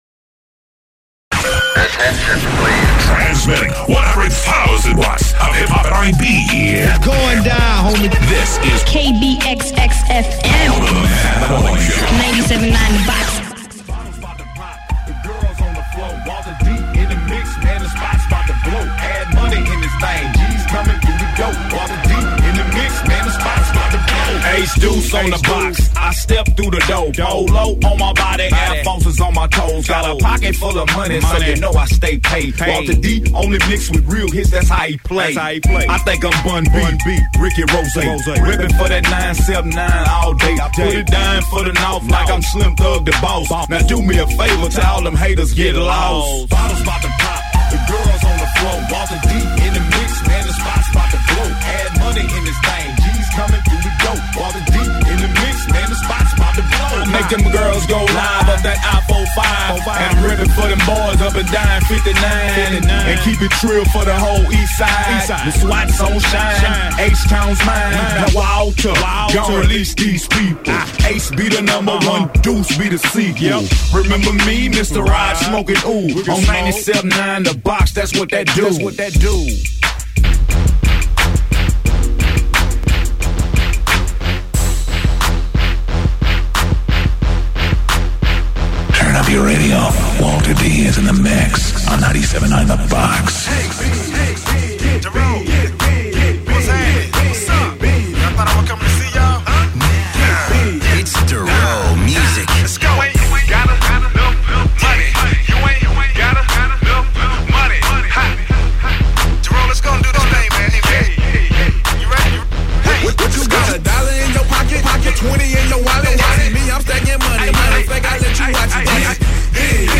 DJ mix